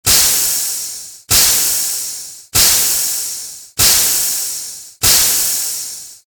Звуки пара
Короткие струйки пара